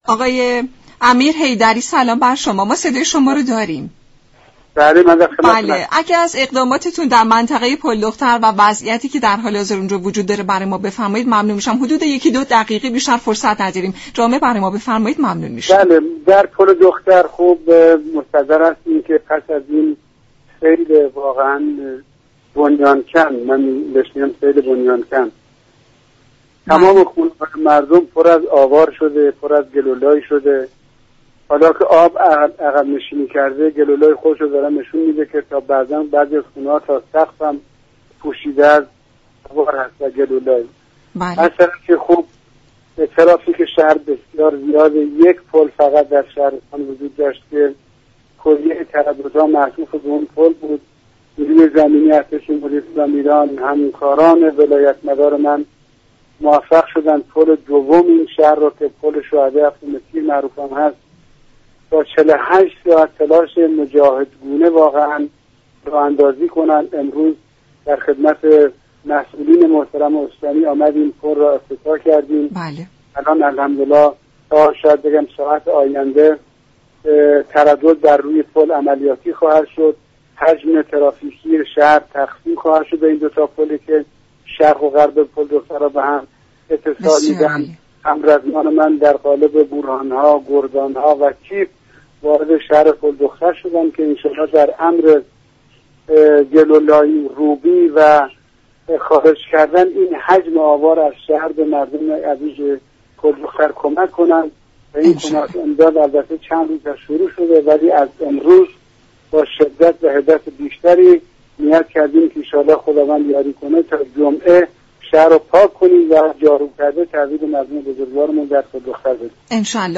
فرمانده نیروی زمینی ارتش در گفت و گو با رادیو ایران گفت: نیروی زمینی ارتش جمهوری اسلامی توانست پل شهدای هفتم تیر را راه اندازی كنند.